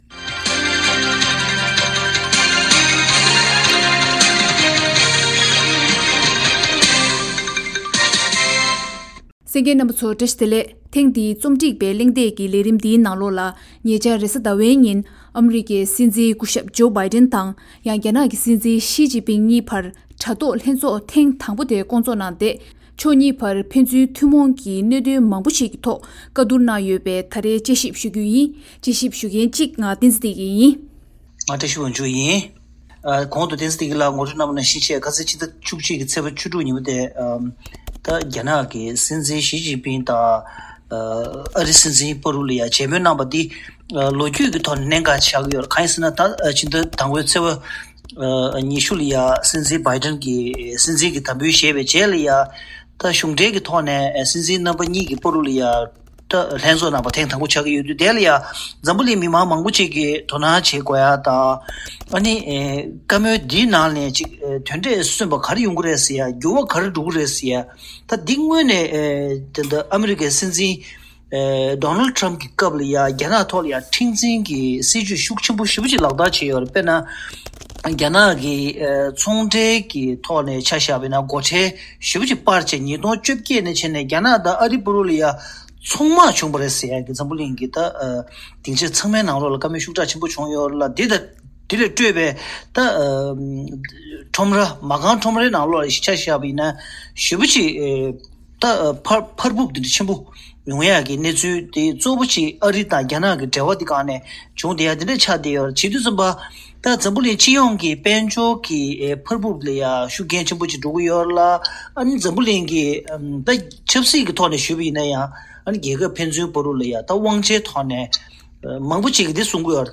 འགོ་ཁྲིད་གཉིས་ཀྱི་མཇལ་མོལ་དང་འབྲེལ་བའི་ཐད་དཔྱད་བསྡུར་གླེང་མོལ་ཞུས་པར་གསན་རོགས་ཞུ།